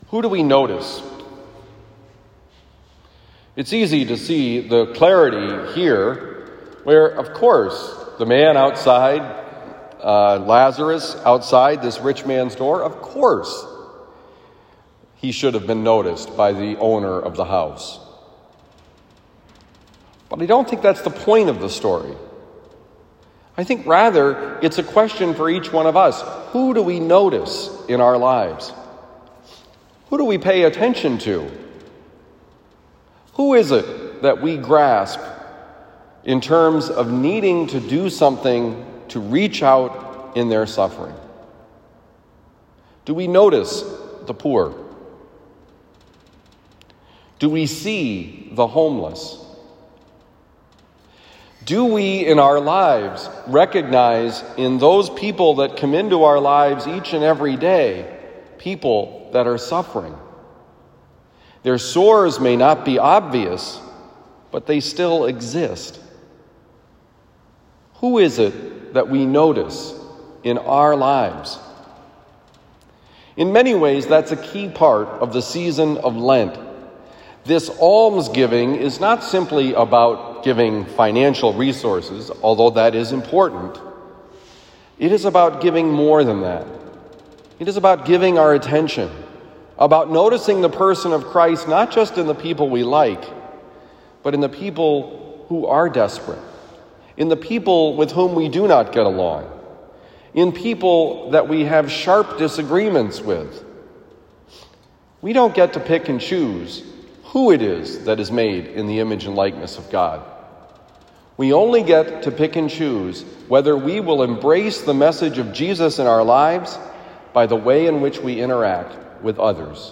Homily for Thursday, March 4, 2021
Given at Christian Brothers College High School, Town and Country, Missouri.